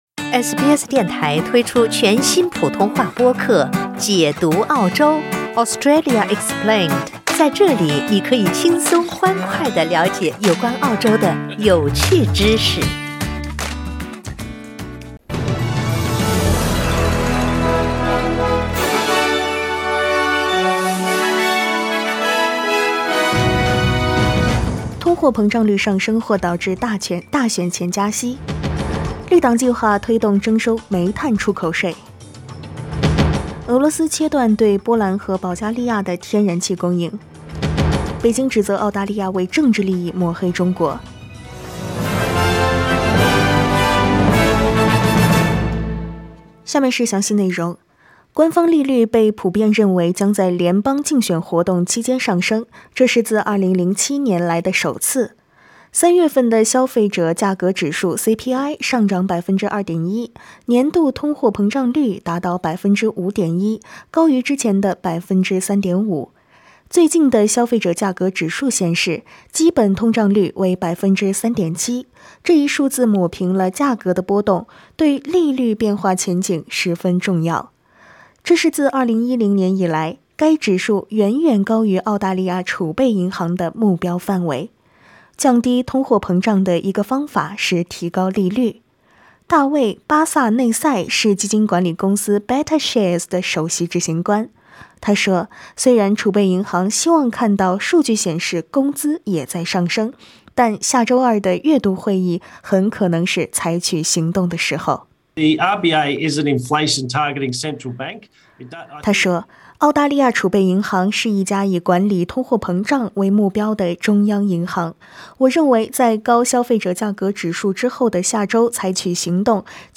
SBS早新闻（4月28日）
SBS Mandarin morning news Source: Getty Images